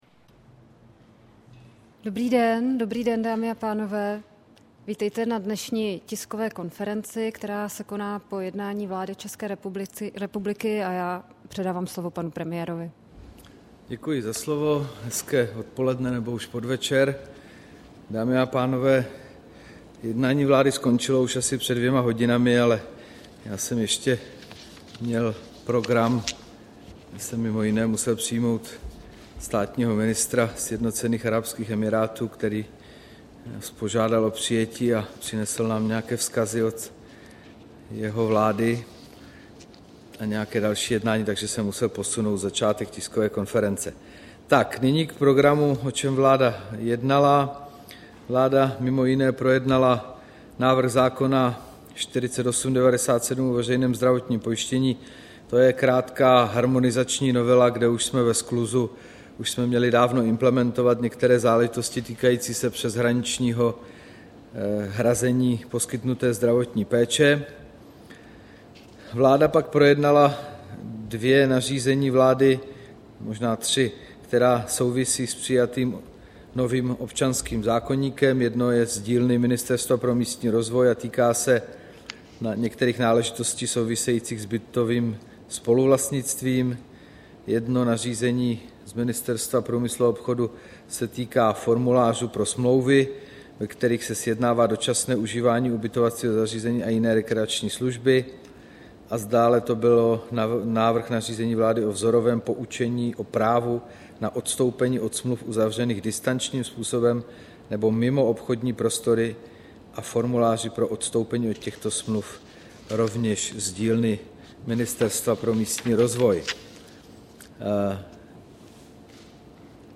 Tisková konference po jednání vlády, 30. října 2013